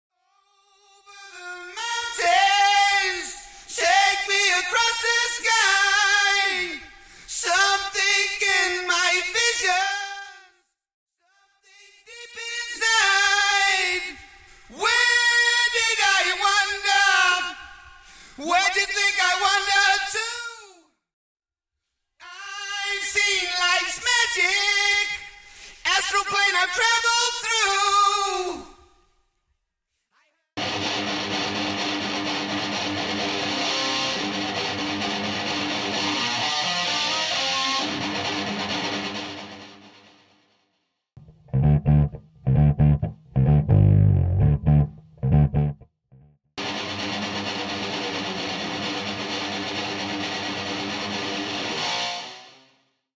Studio Bassline Guitars Stem
Studio Cymbals Stem
Studio Guitars Stem
Studio Kick Stem
Studio More Strings Stem
Studio Snares Stem